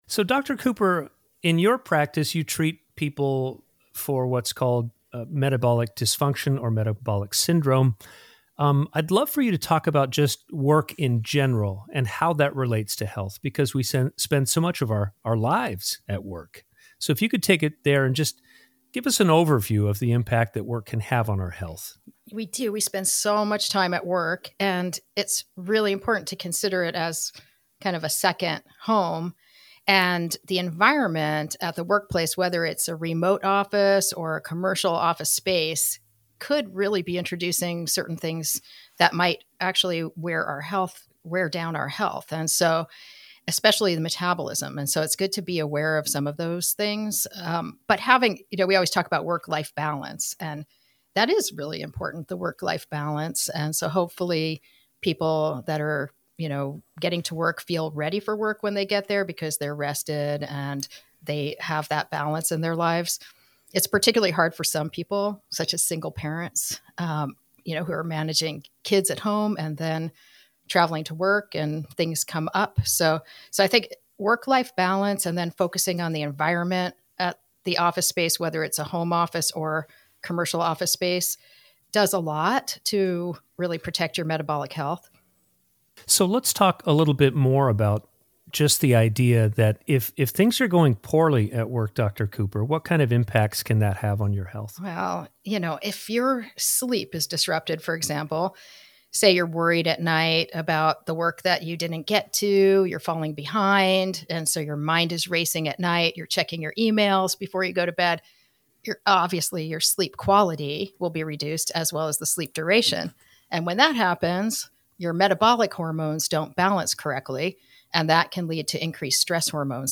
Listen to Full Audio Listen to Short Audio Watch Short Video Welcome to “Sidekick Sessions,” a spinoff of the “BEATS WORKING” podcast, where we gather the team at WORKP2P and do a deep dive with a previous guest.